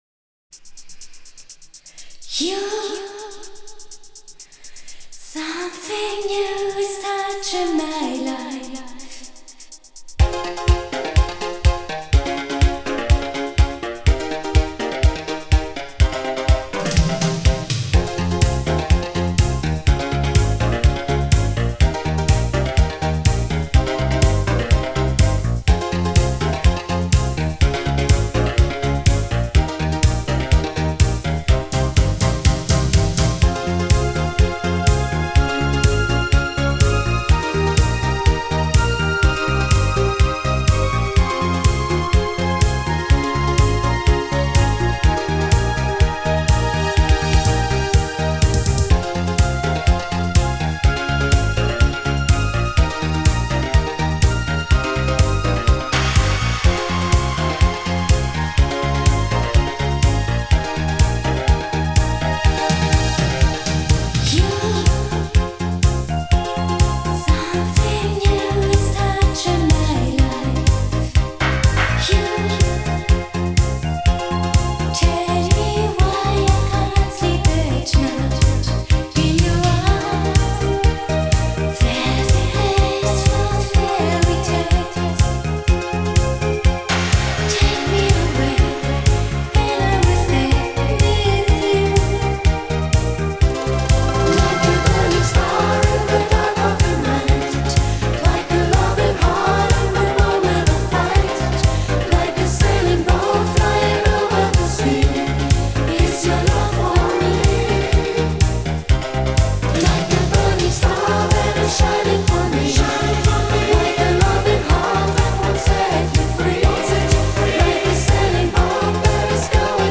Italo Disco / Synthpop Альбом